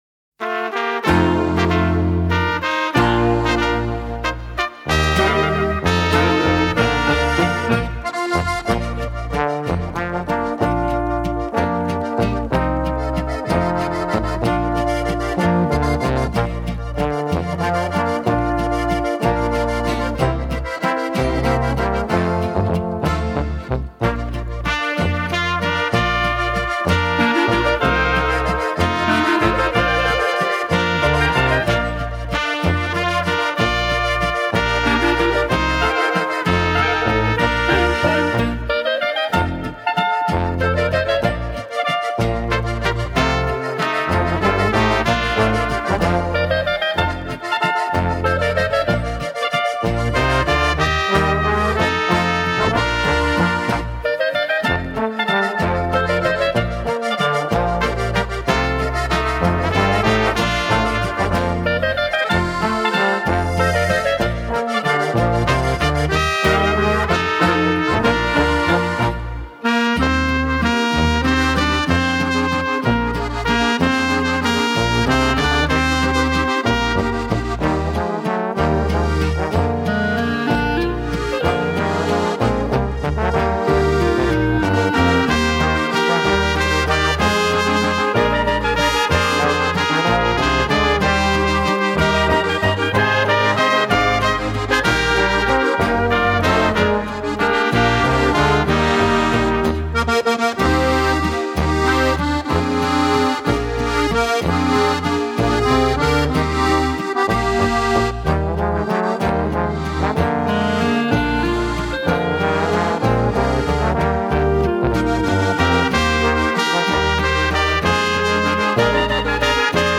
Gattung: für Oberkrainer-Besetzung ab 5 - 8 Musiker
Besetzung: Volksmusik/Volkstümlich Weisenbläser